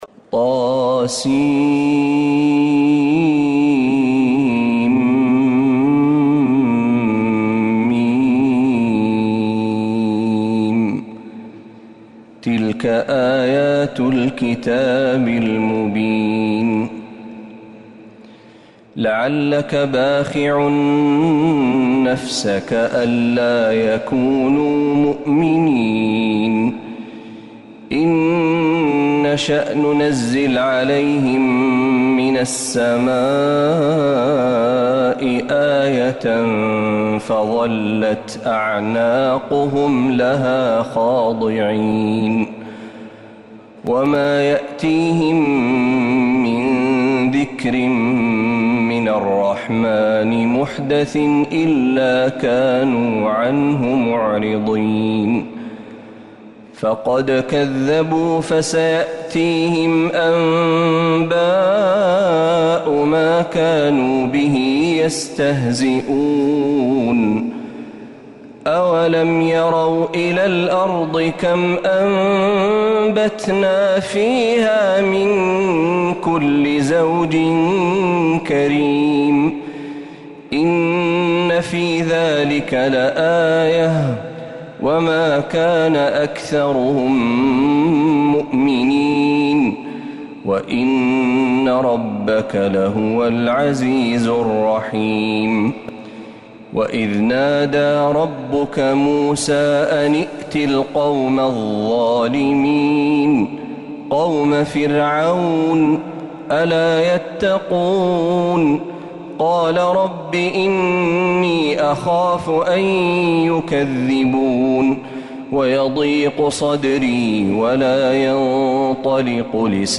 سورة الشعراء كاملة من الحرم النبوي